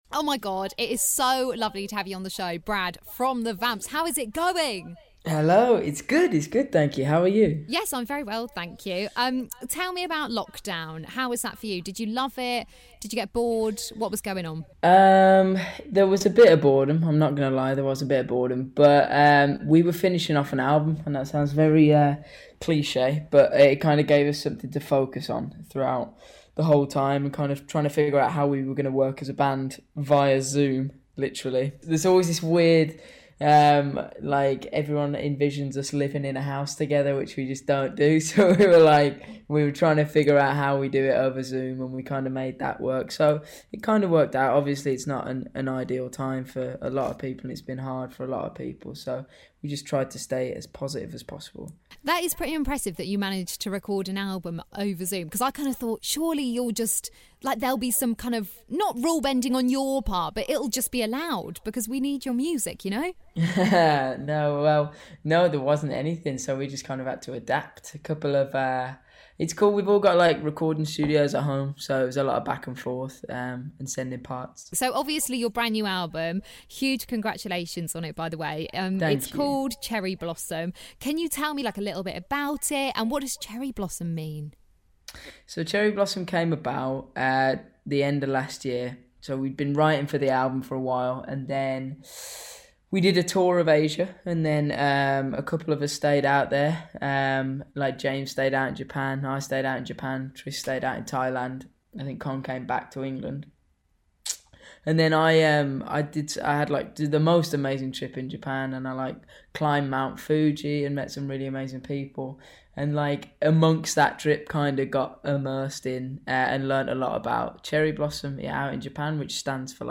chats to Brad from The Vamps about their new album Cherry Blossom